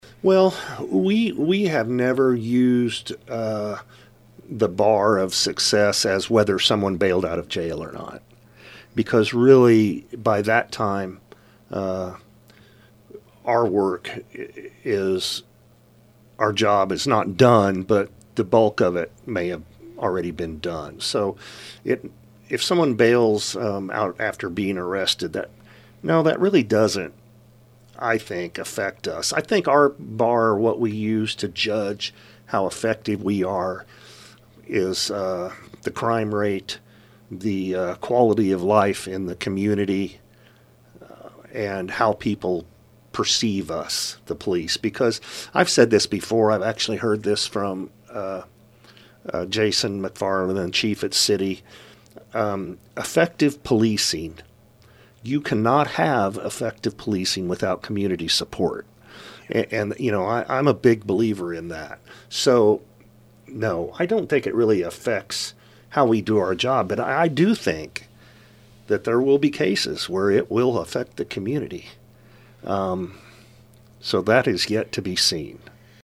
SAFE-T Act Details Explained In Interview With Effingham County Sheriff Paul Kuhns
safe-t-act-interview-part-3.mp3